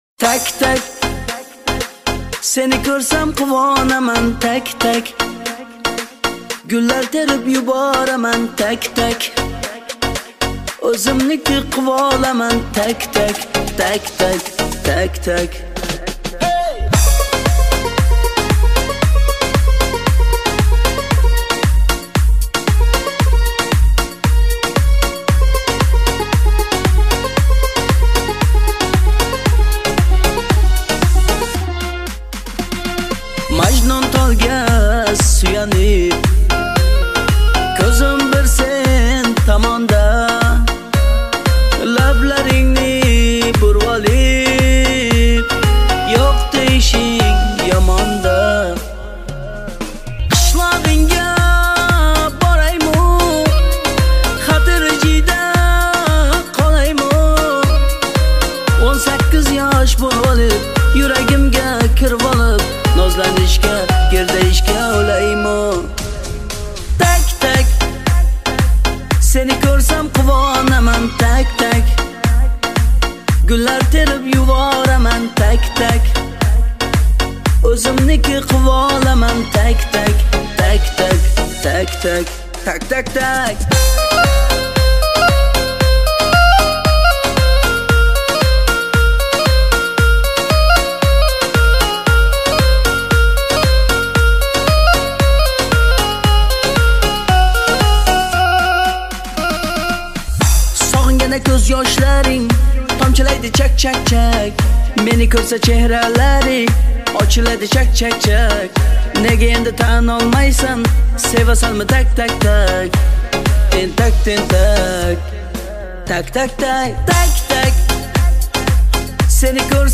Кавказская музыка
кавер версия